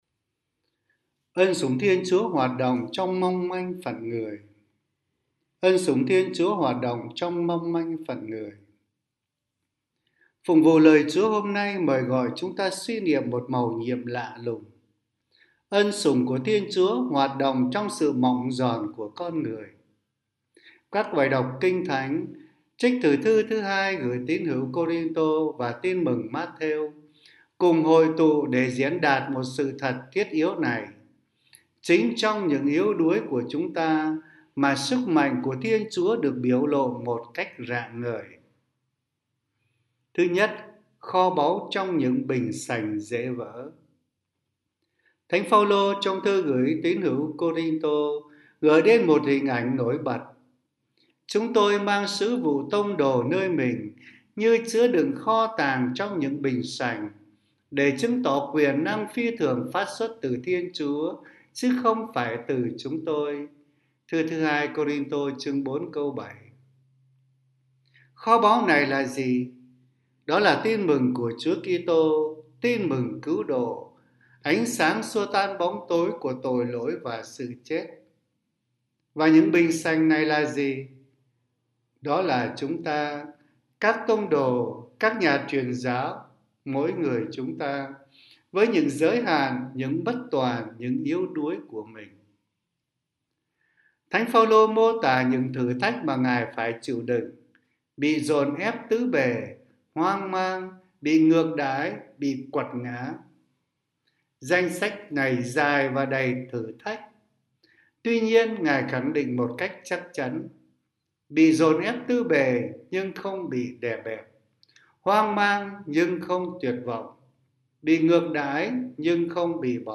Suy niệm hằng ngày